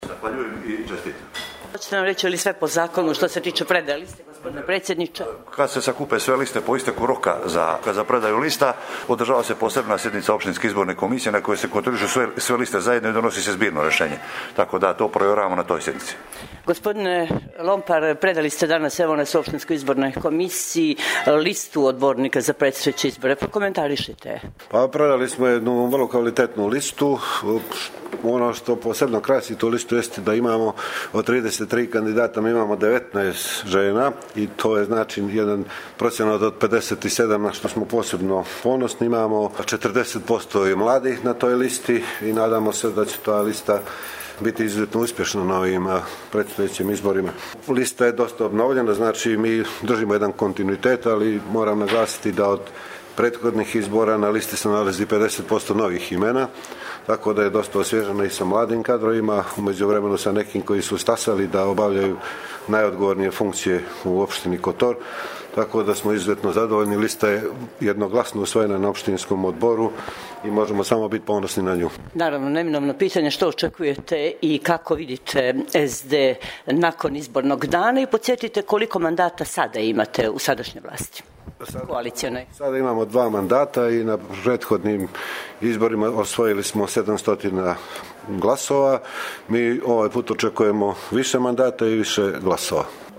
Sa predaje Izborne liste SD Kotor
Sa-predaje-Izborne-liste-SD-Kotor.mp3